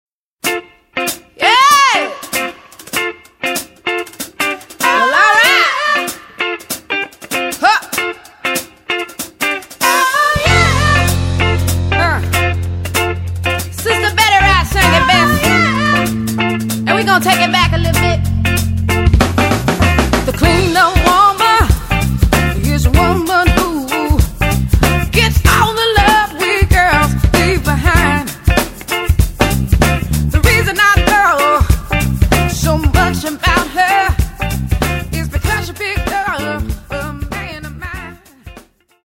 往年のSOUL/FUNKクラシックを完璧に歌いこなす珠玉のカバー集を緊急リリース!